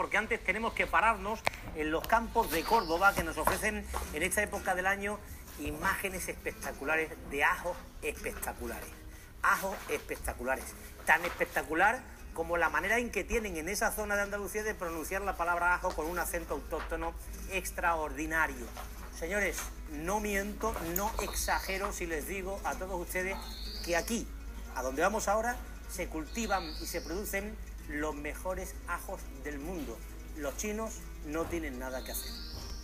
2. El andaluz
Escucha el siguiente audio extraído del programa Andalucía Directo (2/VI/2020) y señala las características del andaluz que veas en él.